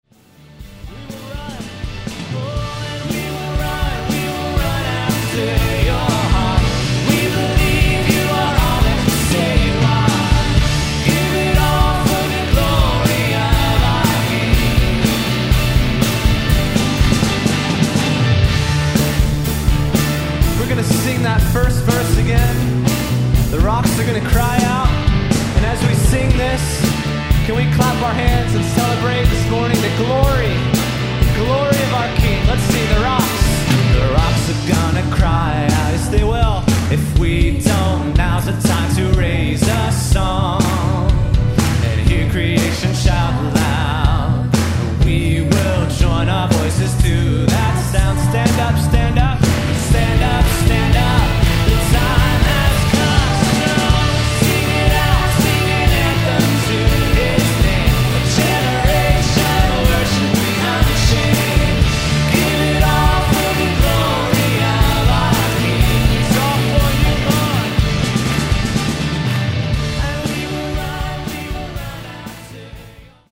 glory-of-our-king-clap.mp3